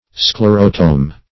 Search Result for " sclerotome" : The Collaborative International Dictionary of English v.0.48: Sclerotome \Scler"o*tome\ (skl[e^]r"[-o]*t[=o]m or skl[=e]r"[-o]*t[=o]m), n. [Gr. sklhro`s hard + te`mnein to cut.]